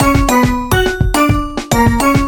Очередные фокусы с перкуссией happy